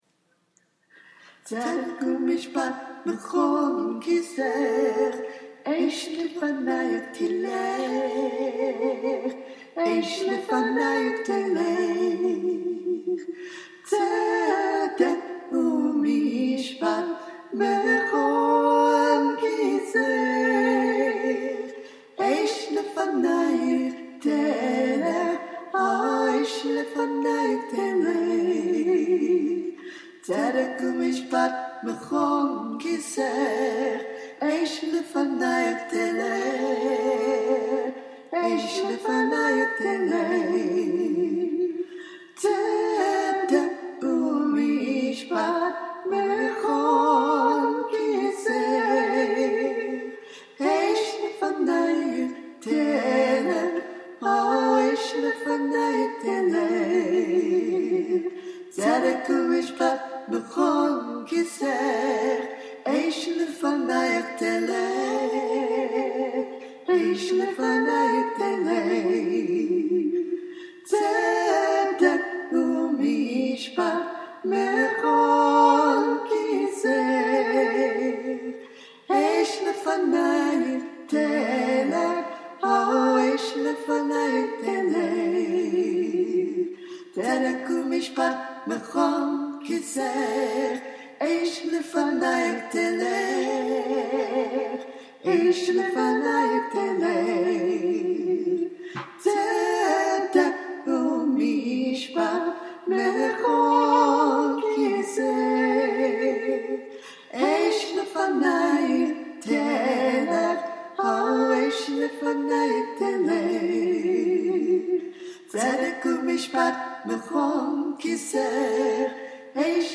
Your Throne Harmony
YourThroneTzedek_harmony.mp3